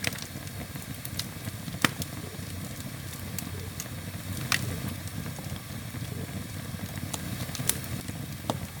Fire_Loop.ogg